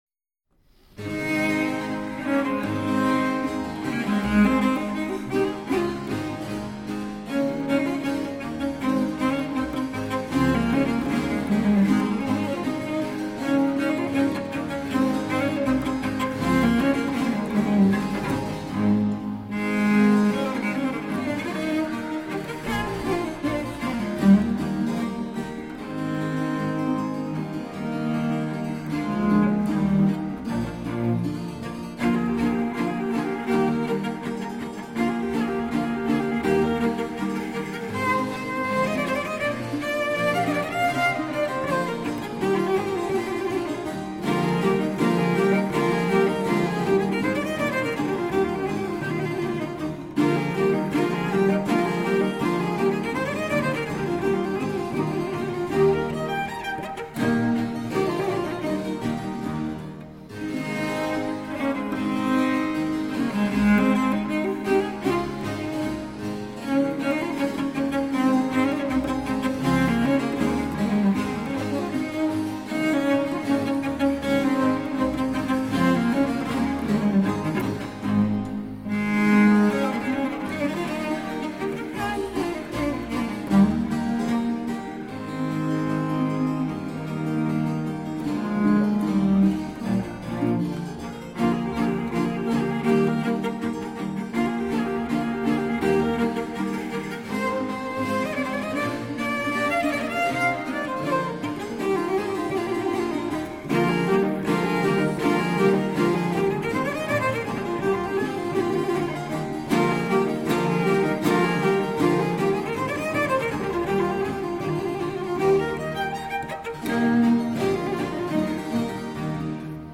大提琴奏鸣曲与协奏曲
Recorded in: Stereo